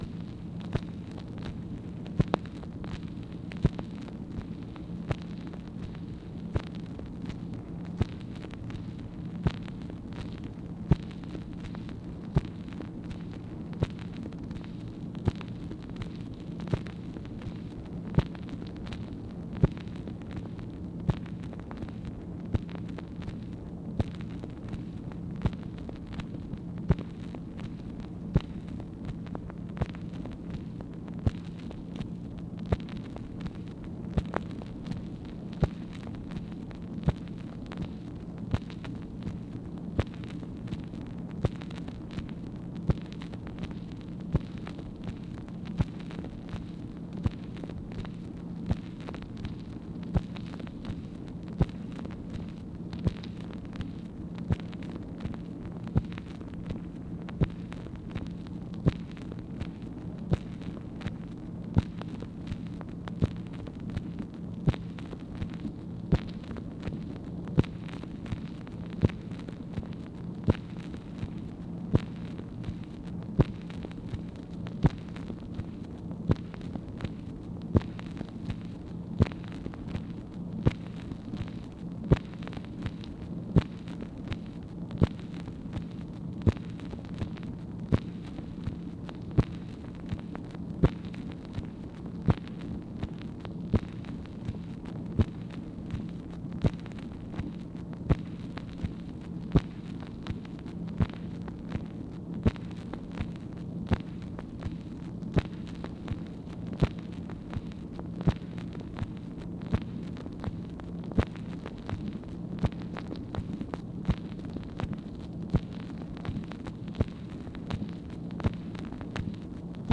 OFFICE NOISE, April 21, 1964
Secret White House Tapes | Lyndon B. Johnson Presidency